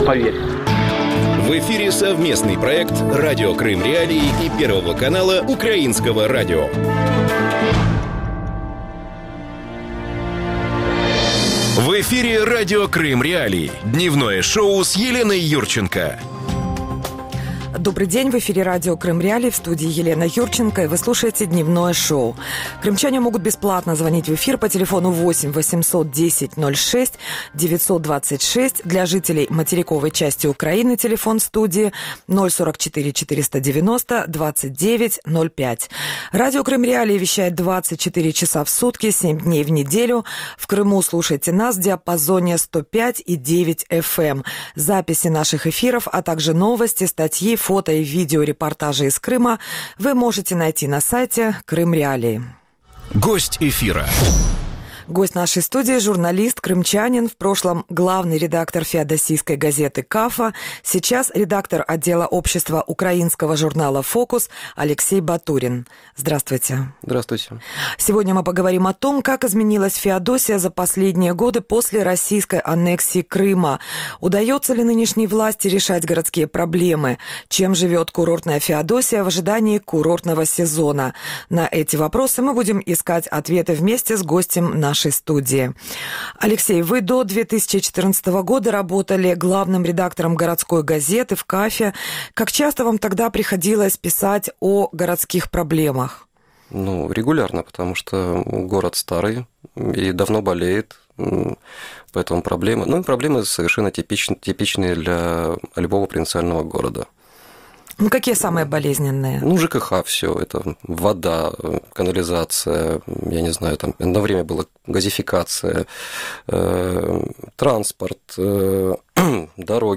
Об этом – в программе «Ценные мысли» в эфире Радио Крым.Реалии с 12:10 до 12:40.